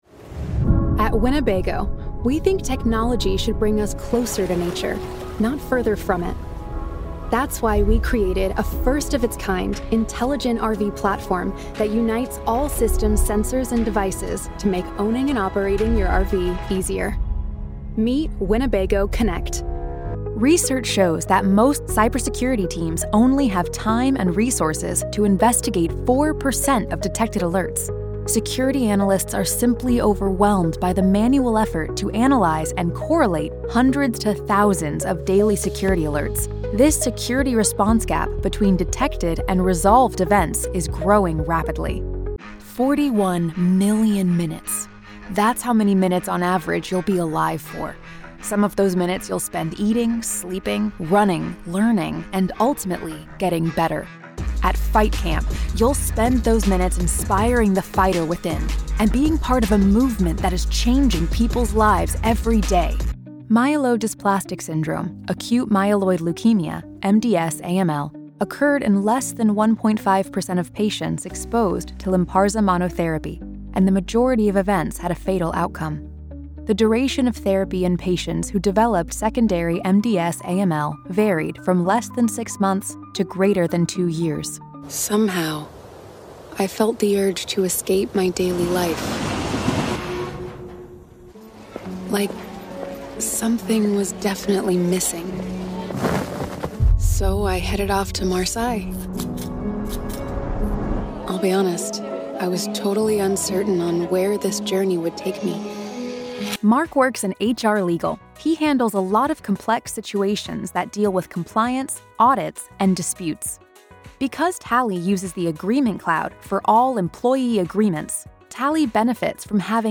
New York based voice actor specializing in warm, authoritative and sincere reads.
English (North American)
Can do any preferred video/audio call for live sessions in a treated professional home studio.